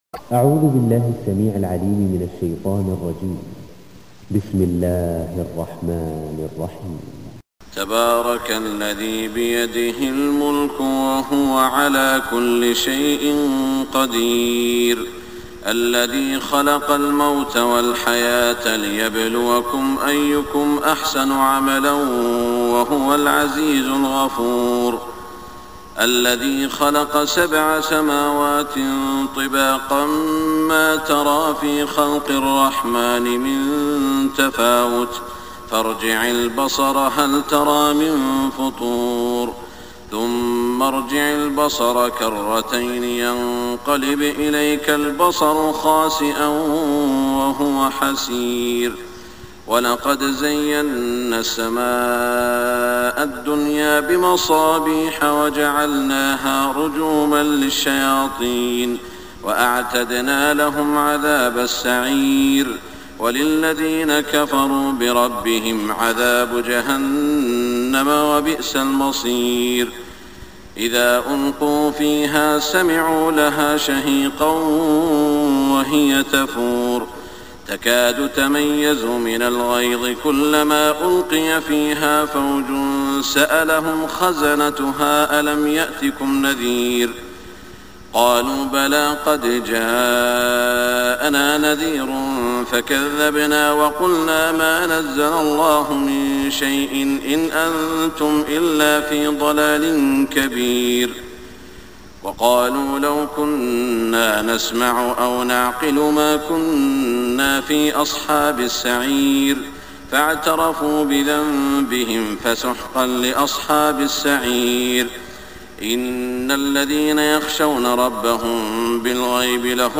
صلاة الفجر 1425 سورة الملك > 1425 🕋 > الفروض - تلاوات الحرمين